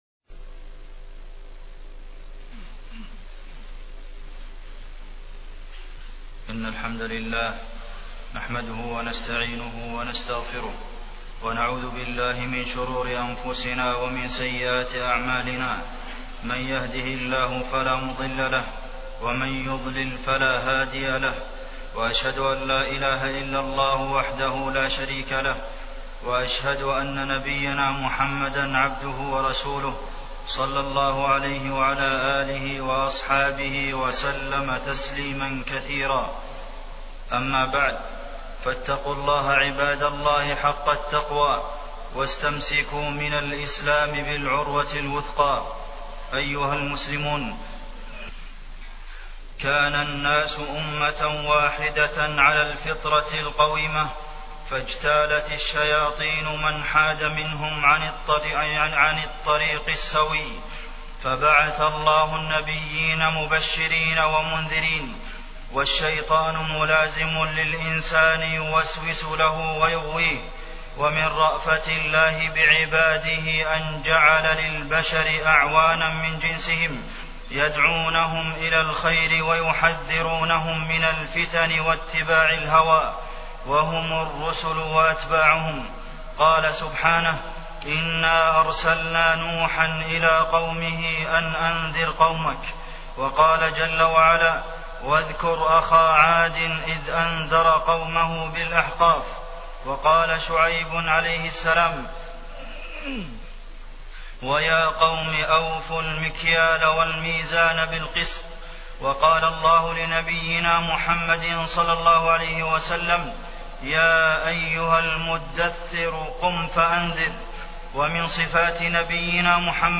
تاريخ النشر ٥ ربيع الثاني ١٤٢٩ هـ المكان: المسجد النبوي الشيخ: فضيلة الشيخ د. عبدالمحسن بن محمد القاسم فضيلة الشيخ د. عبدالمحسن بن محمد القاسم الأمر بالمعروف والنهي عن المنكر